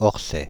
Fr-Paris--Orsay.ogg